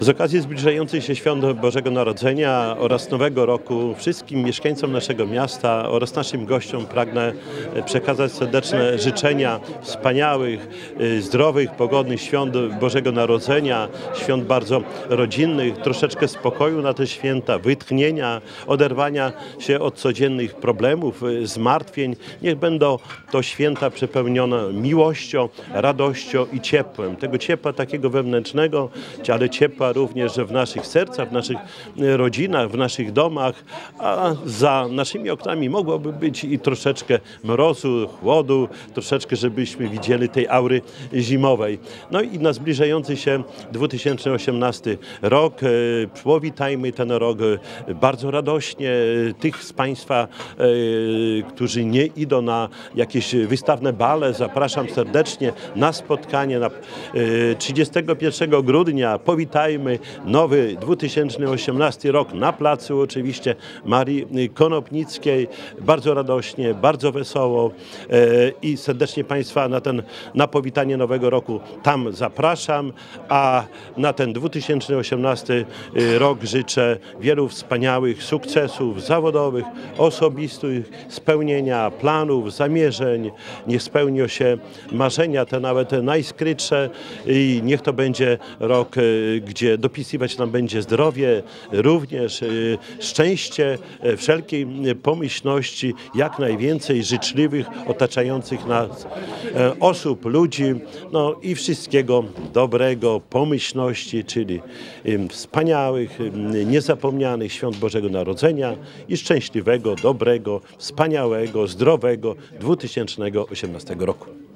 Spokojnych świąt, oderwania się od codziennych zmartwień, ciepła i radości życzy Czesław Renkiewicz, prezydent Suwałk.